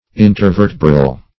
Search Result for " intervertebral" : Wordnet 3.0 ADJECTIVE (1) 1. pertaining to the space between two vertebrae ; The Collaborative International Dictionary of English v.0.48: Intervertebral \In`ter*ver"te*bral\, a. (Anat.)
intervertebral.mp3